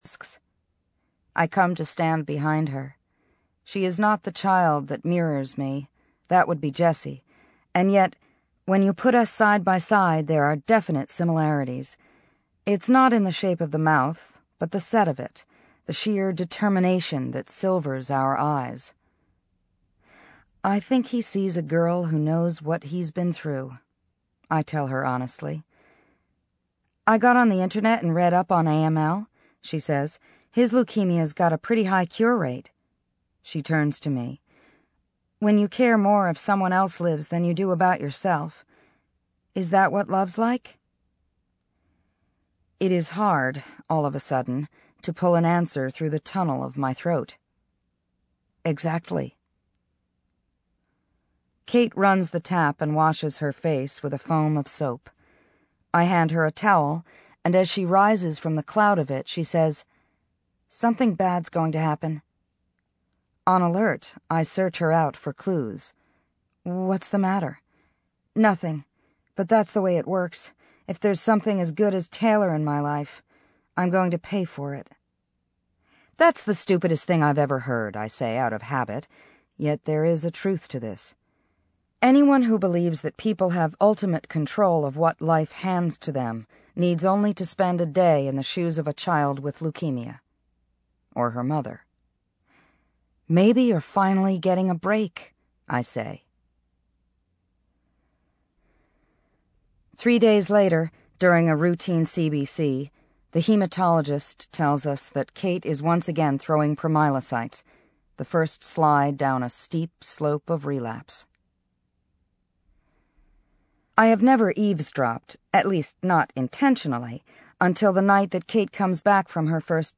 英文广播剧在线听 My Sister's Keeper（姐姐的守护者）125 听力文件下载—在线英语听力室